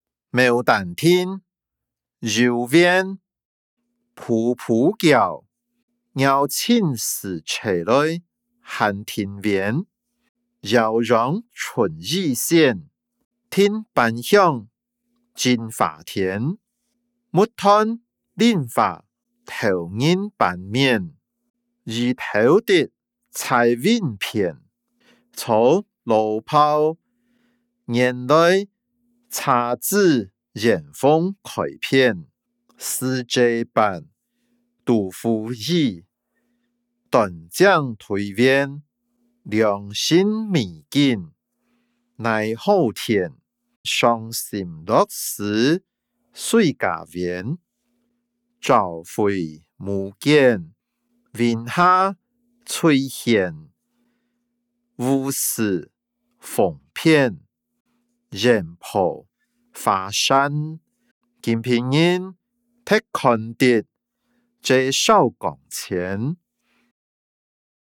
詞、曲-牡丹亭．遊園音檔(饒平腔)